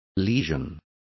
Also find out how lesion is pronounced correctly.